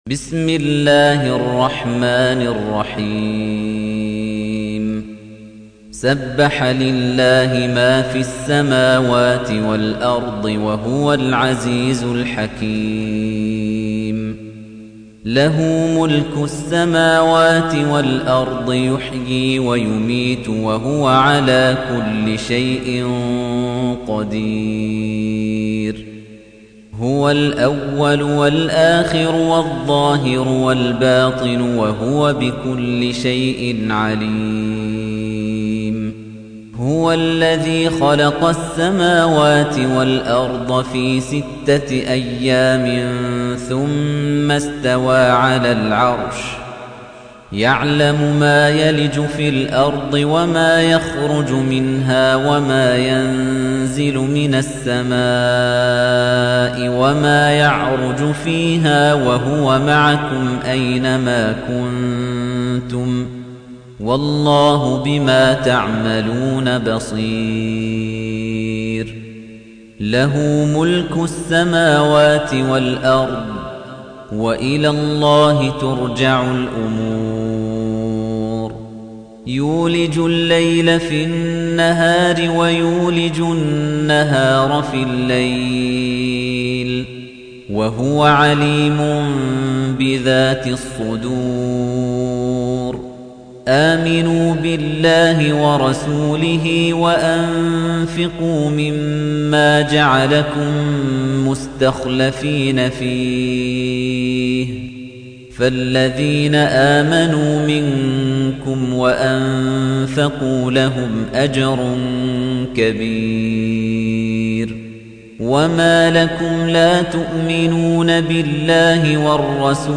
تحميل : 57. سورة الحديد / القارئ خليفة الطنيجي / القرآن الكريم / موقع يا حسين